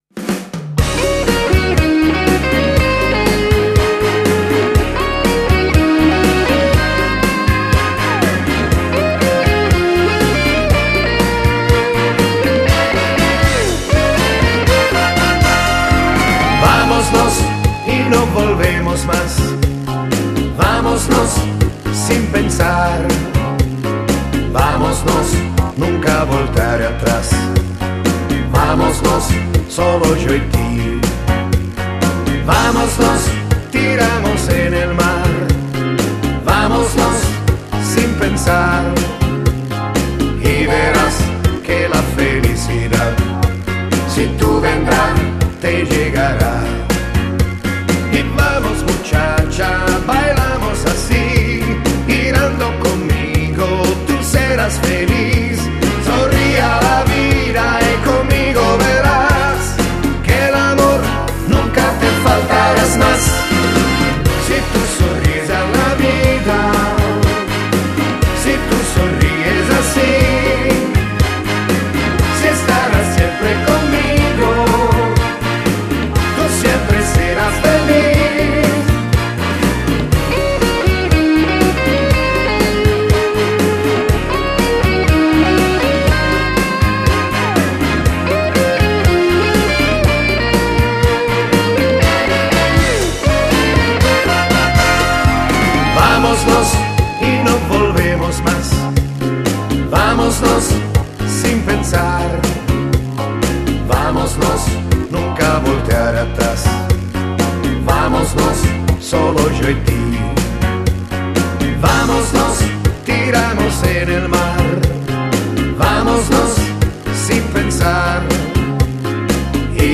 Genere: Cha cha cha